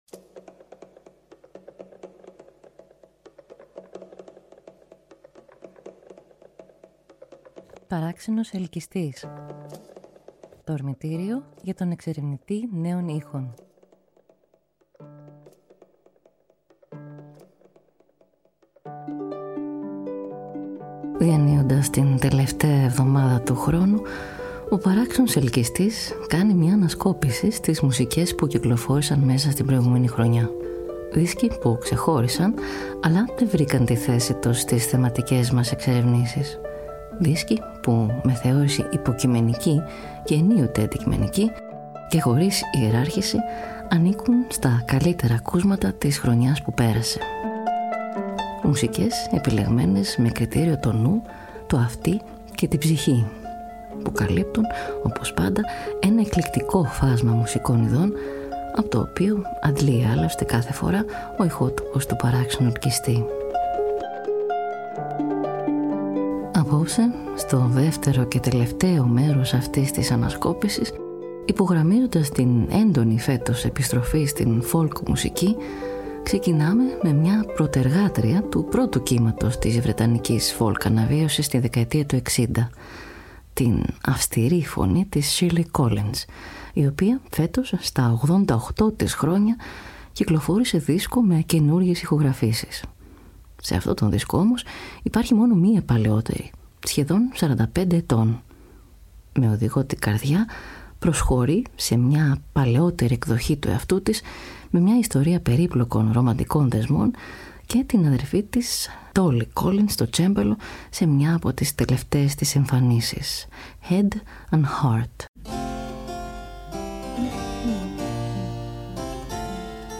[μουσική ανασκόπηση της χρονιάς που τελειώνει – (2/2)]